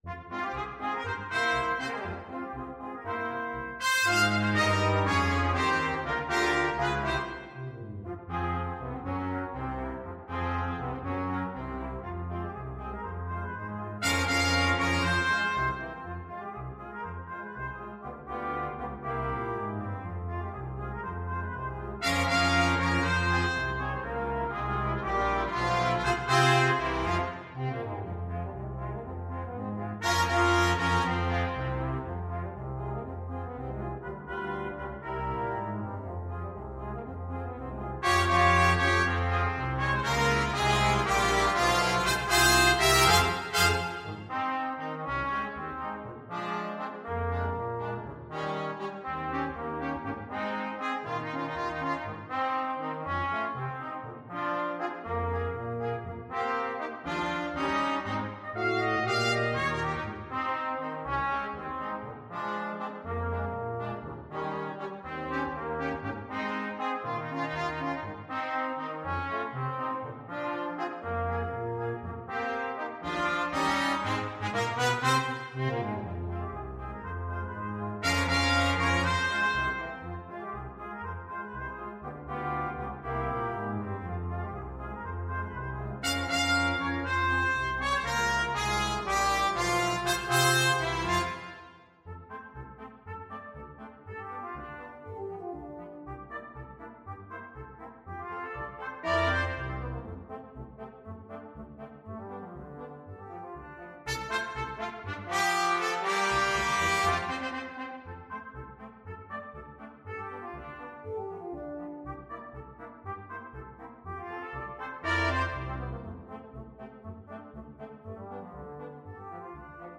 Trumpet 1Trumpet 2French HornTromboneTuba
2/4 (View more 2/4 Music)
Allegretto Misterioso = 120
Classical (View more Classical Brass Quintet Music)